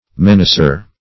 menacer - definition of menacer - synonyms, pronunciation, spelling from Free Dictionary Search Result for " menacer" : The Collaborative International Dictionary of English v.0.48: Menacer \Men"a*cer\ (m[e^]n"[asl]*s[~e]r), n. One who menaces.